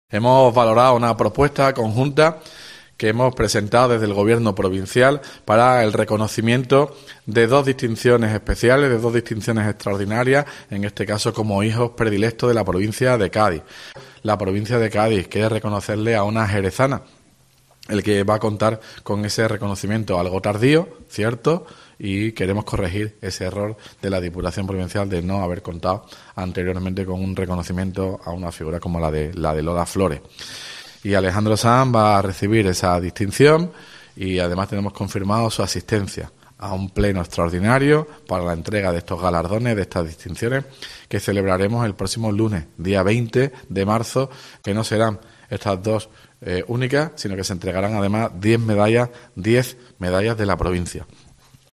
Ruiz Boix, Presidente de la Diputación de Cádiz, habla sobre los hijos predilectos de la provincia 2023